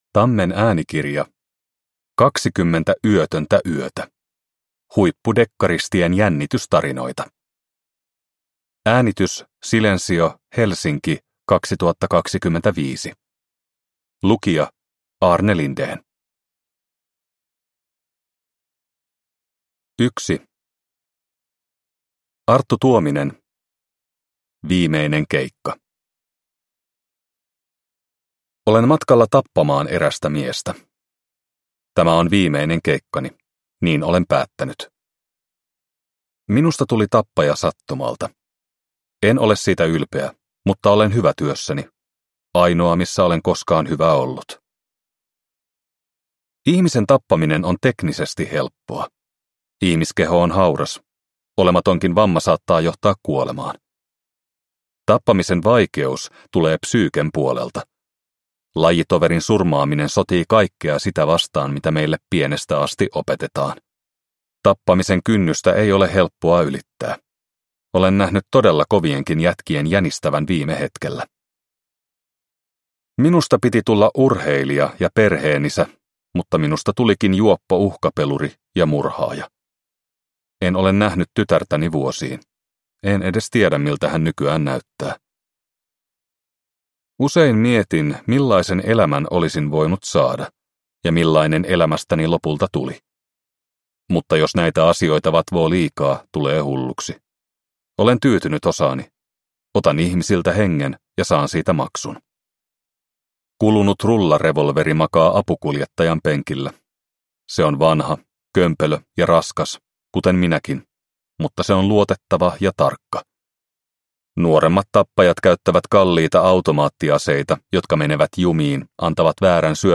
20 yötöntä yötä – Ljudbok